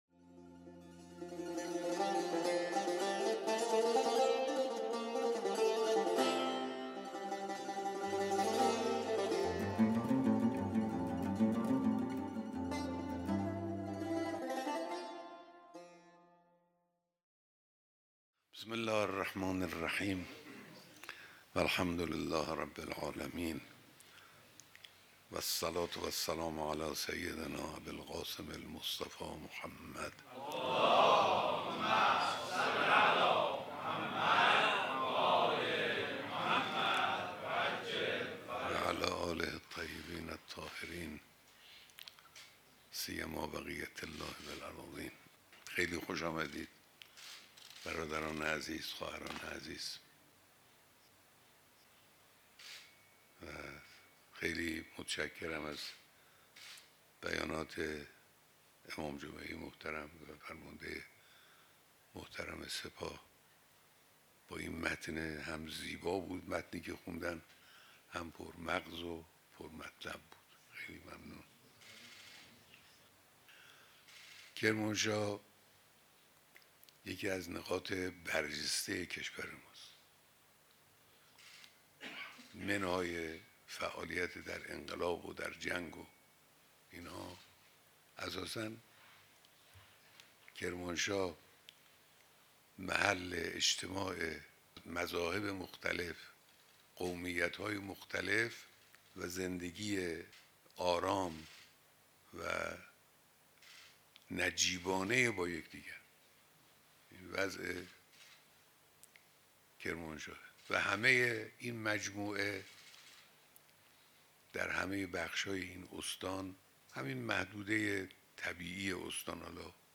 بیانات در دیدار دست‌اندرکاران کنگره ملی شهدای استان کرمانشاه